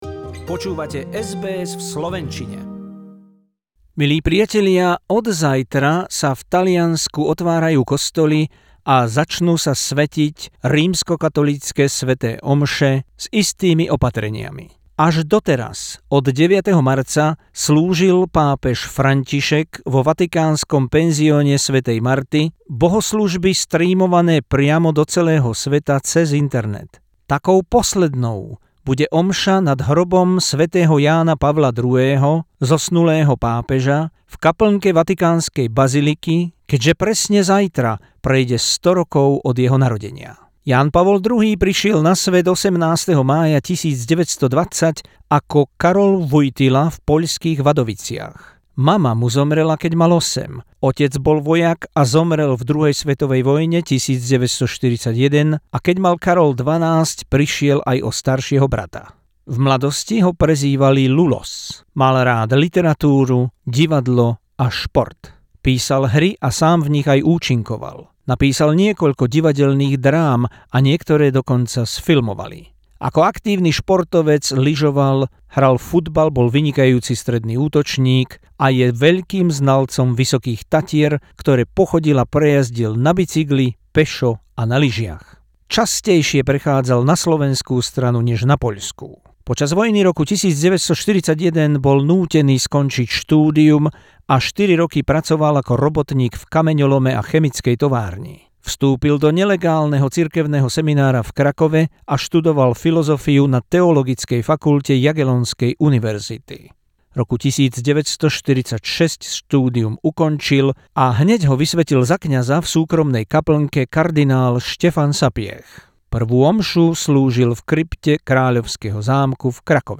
Feature about the first ever Slavic Pope, the Saint John Paul II, originally Polish Cardinal Karol Wojtyla, his trips to Slovakia and love for Slovak nation and mountains. Hundred years anniversary of his birth. He spoke fluent and beautiful Slovak language, officially visited Slovakia three times and privately much more.